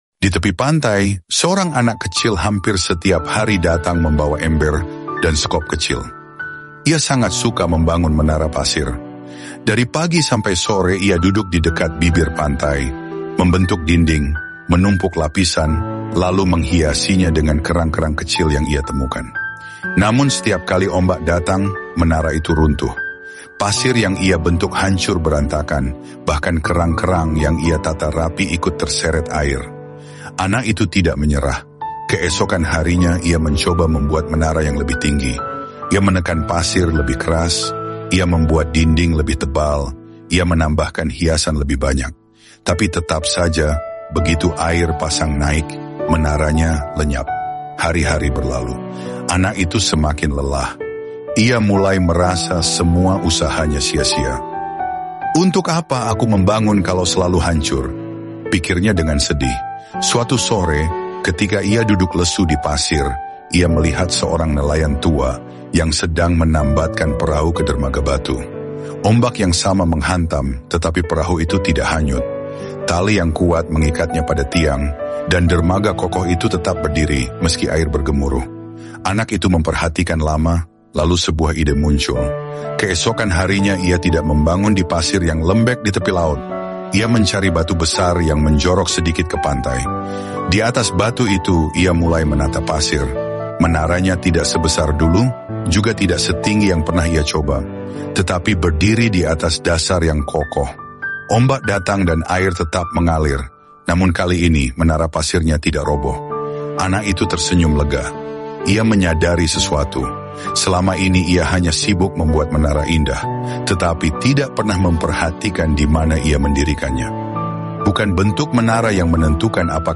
Ilustrasi Khotbah Anak Kecil Sound Effects Free Download